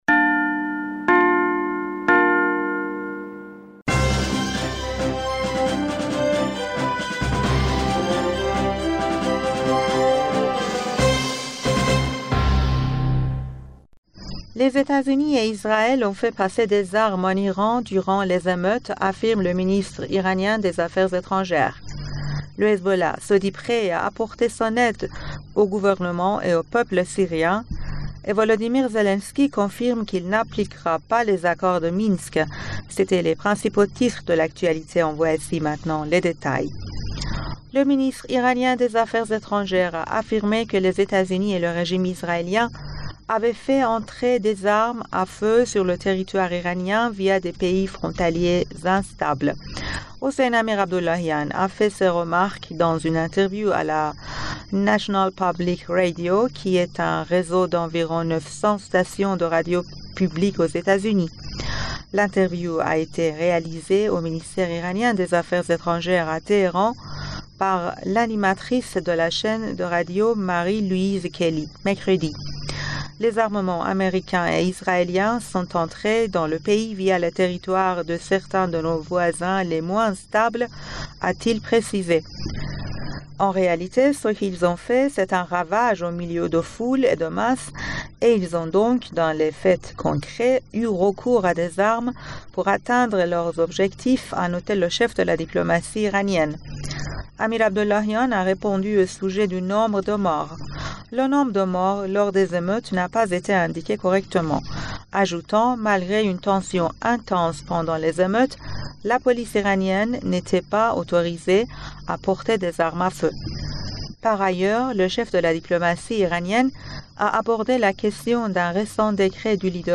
Bulletin d'information du 10 Février